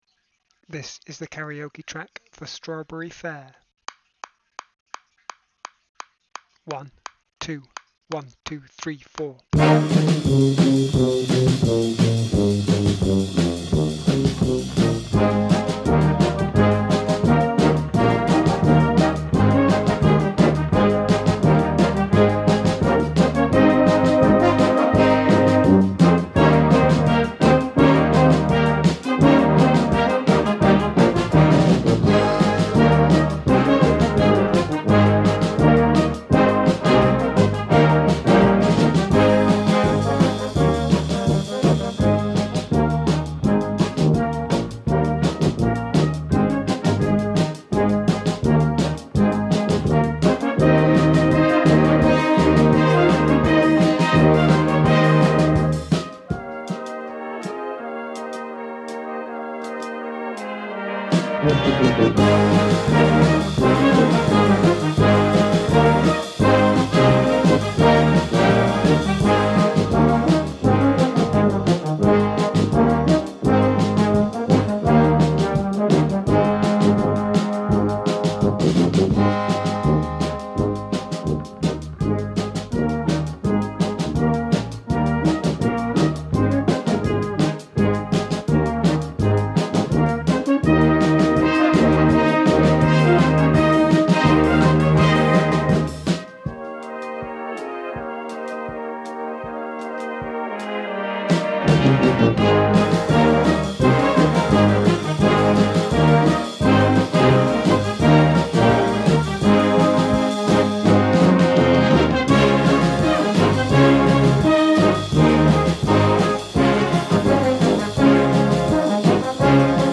strawberry-fair-karaoke-v2.mp3